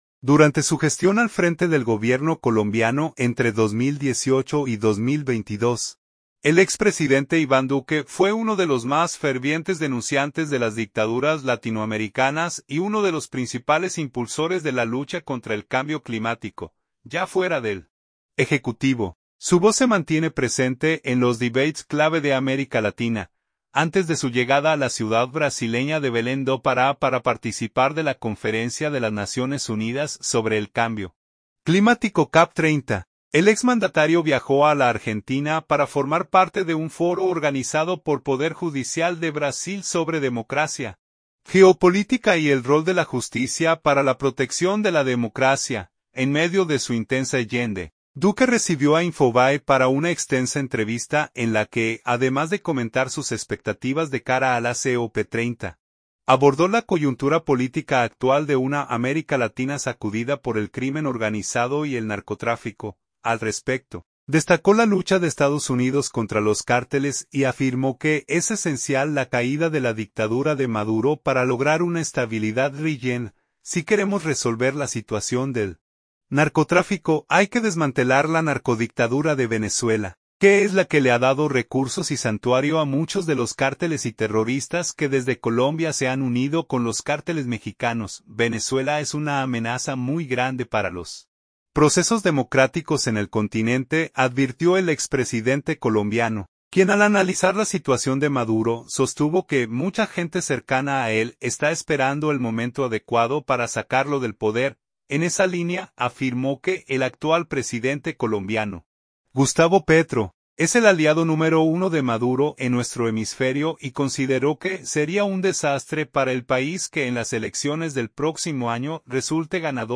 En medio de su intensa agenda, Duque recibió a Infobae para una extensa entrevista en la que, además de comentar sus expectativas de cara a la COP30, abordó la coyuntura política actual de una América Latina sacudida por el crimen organizado y el narcotráfico.